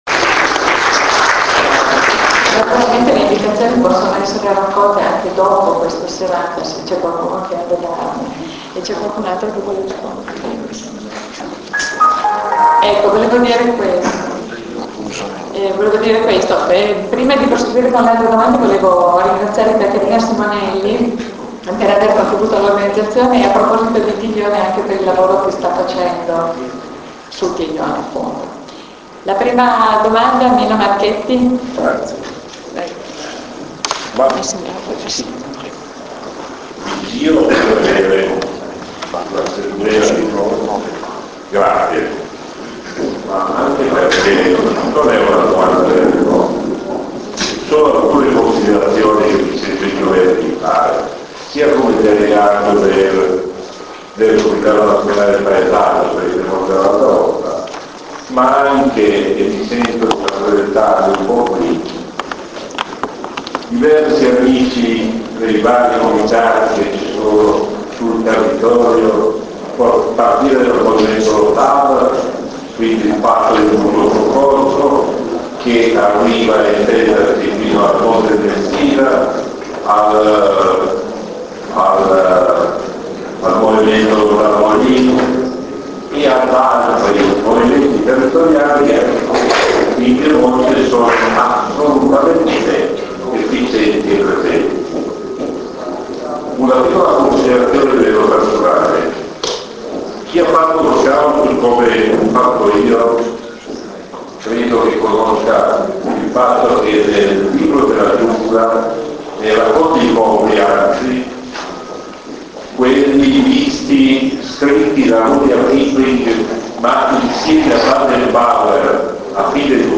a Rocca d'Arazzo - Salone comunale - Sabato 10 maggio 2008 ore 21.00